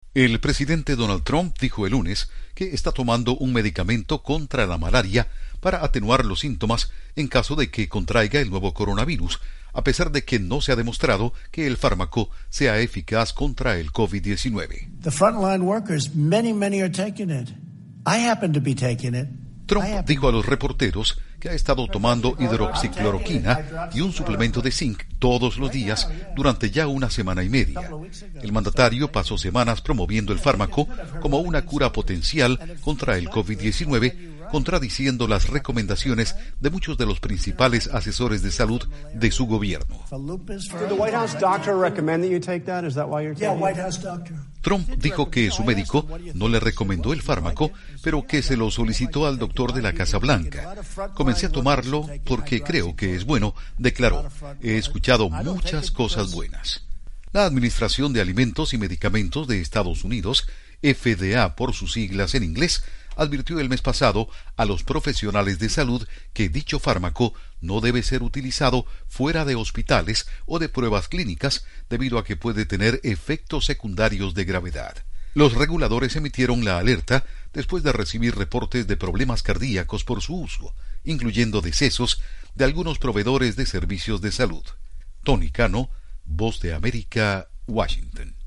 Incluye declaraciones de Trump